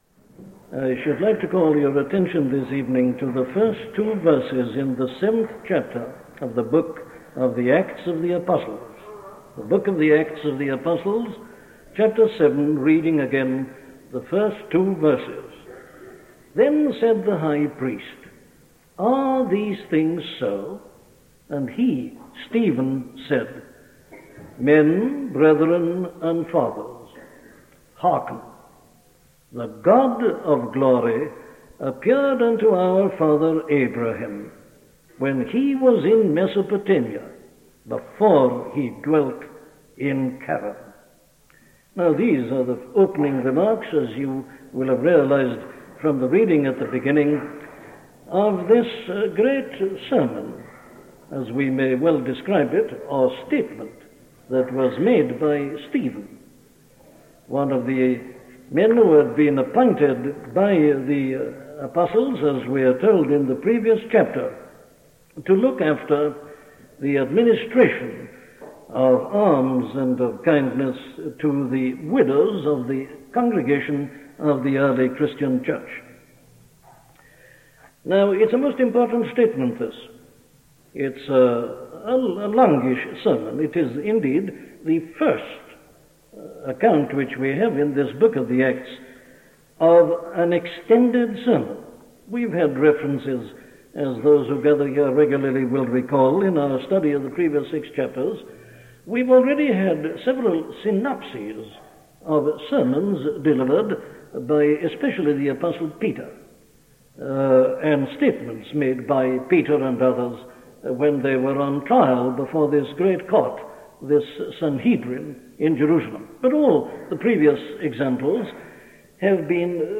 Book of Acts Sermons: Ananias and Sapphira, Acts 5:1-11 & More | Dr. Lloyd-Jones
Dr. Lloyd-Jones’s sermon series on the Book of Acts is comprised of 119 sermons, plus 24 other sermons preached at Westminster Chapel on the book of Acts.
This series of sermons were preached on Sunday evenings between 1965 and 1968.